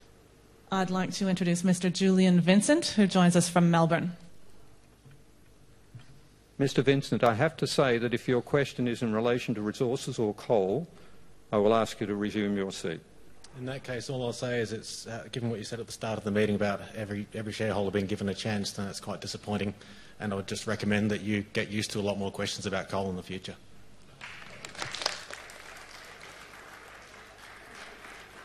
Fossil fuels were top of the agenda at ANZ’s annual general meeting today